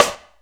Wod_Snr.wav